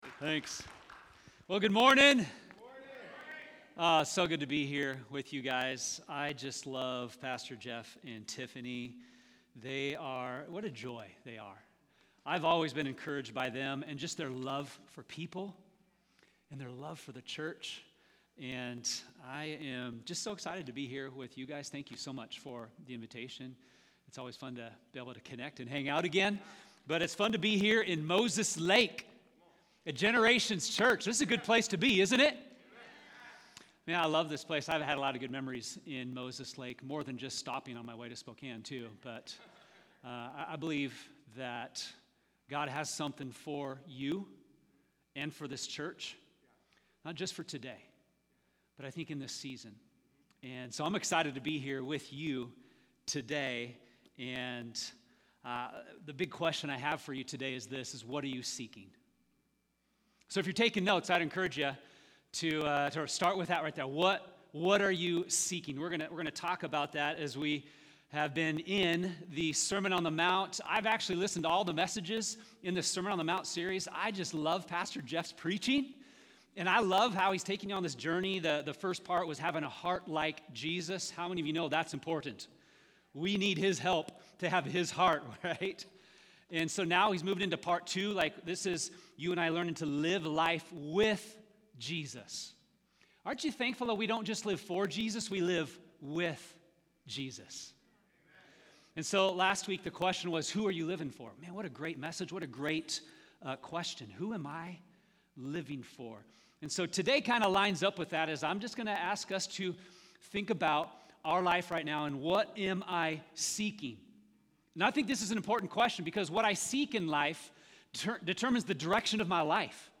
gives a powerful message on Matthew 6 and how to normalize a passionate pursuit of Jesus. Ending with a great daily habit of seeking him and enjoying him.